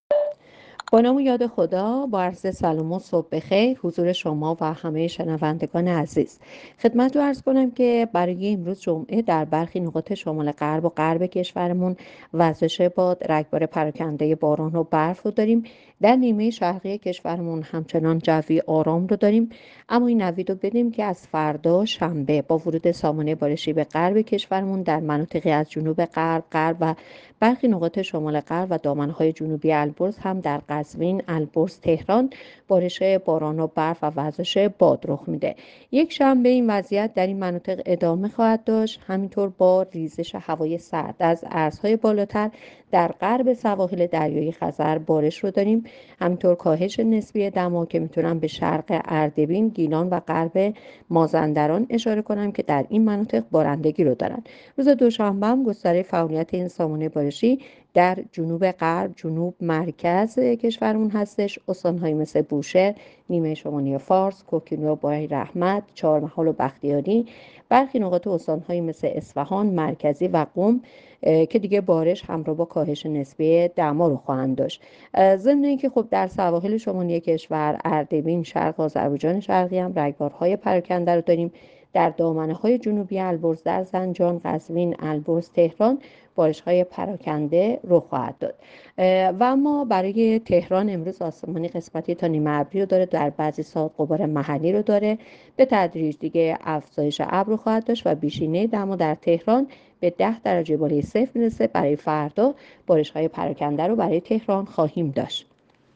گزارش رادیو اینترنتی پایگاه‌ خبری از آخرین وضعیت آب‌وهوای ۵ بهمن؛